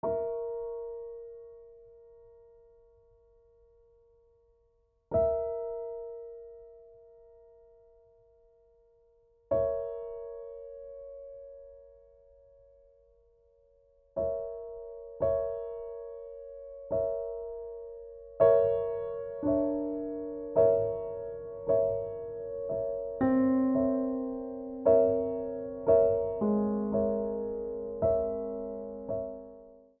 für Klavier Solo
Beschreibung:Klassik; Solowerke
Besetzung:Klavier Solo